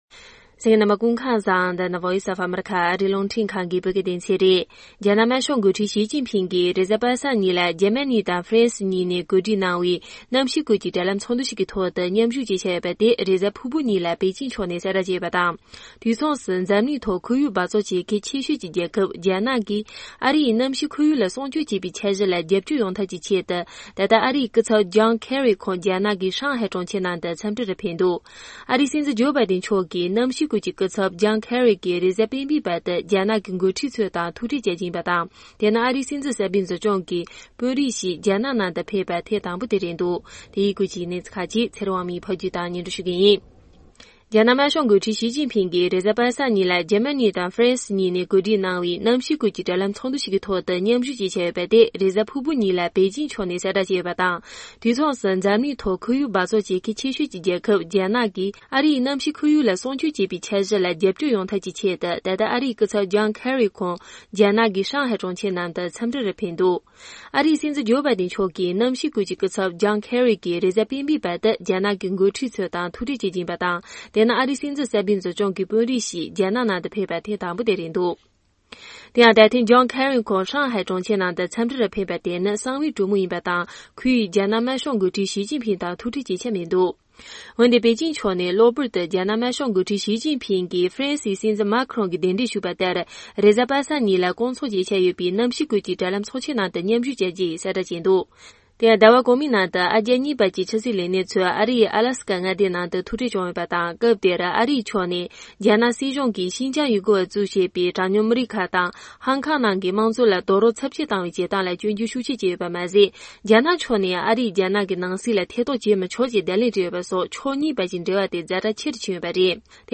ཕབ་སྒྱུར་དང་སྙན་སྒྲོན་ཞུ་ཡི་རེད།